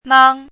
nāng
nang1.mp3